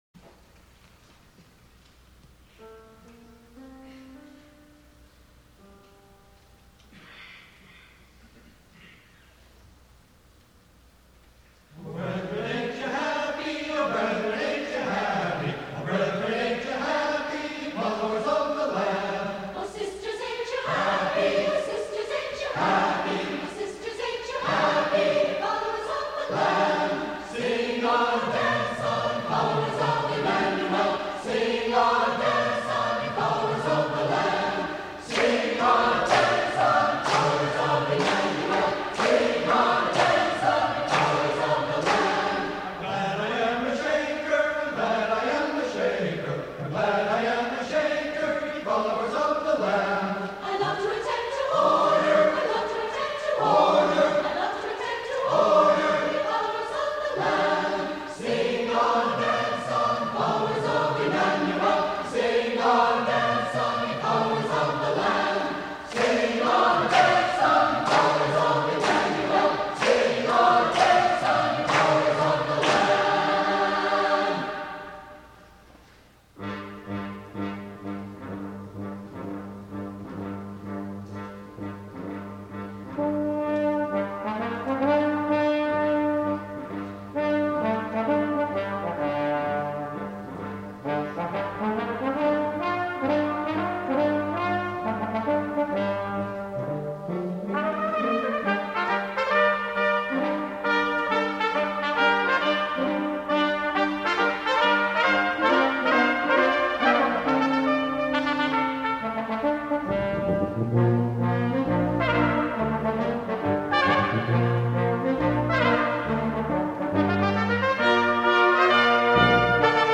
for Brass Quintet (1994)
with singing
is a jovial song which features the Tuba.